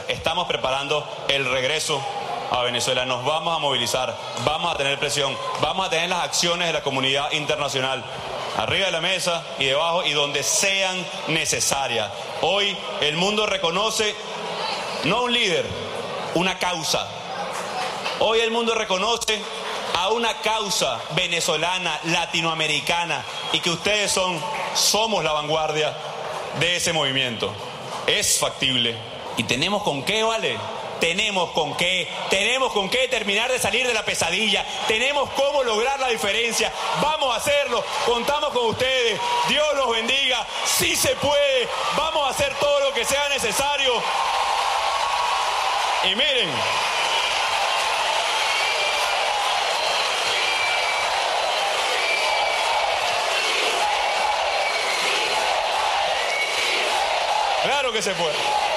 Declaraciones del embajador de Venezuela en Washington, DC, Carlos Vecchio